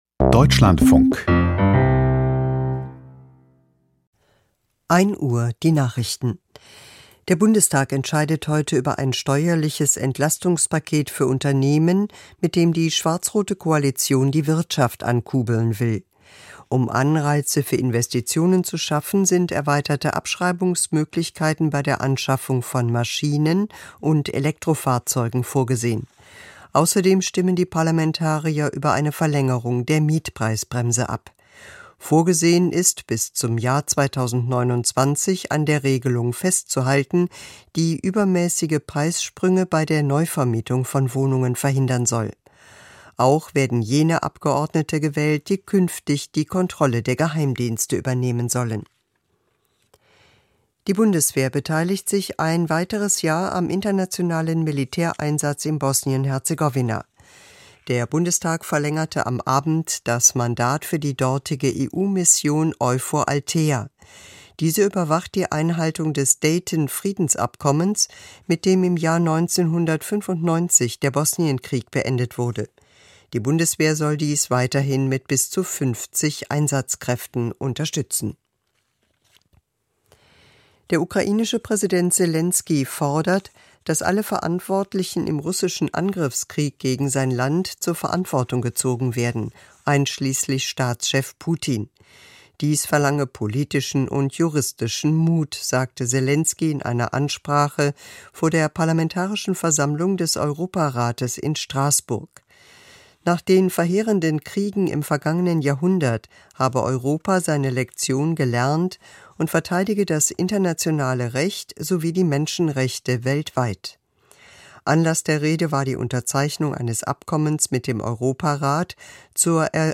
Die Nachrichten vom 26.06.2025, 01:00 Uhr
Aus der Deutschlandfunk-Nachrichtenredaktion.